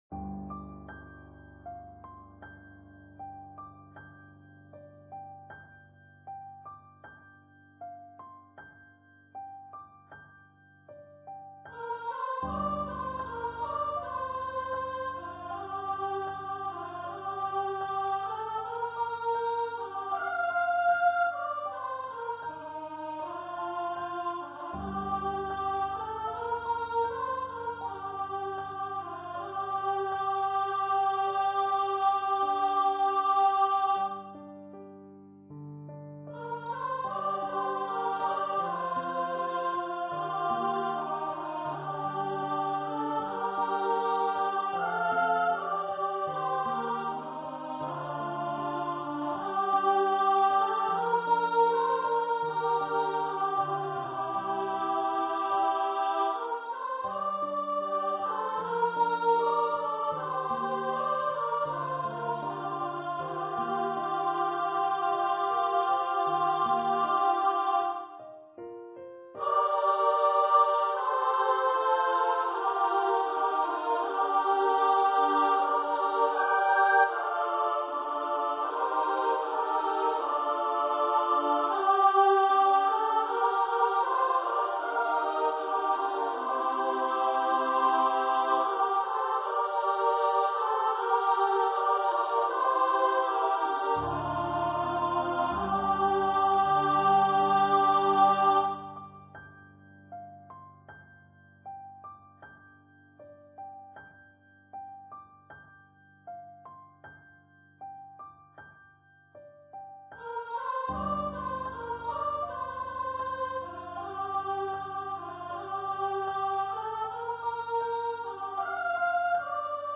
for female voice choir and piano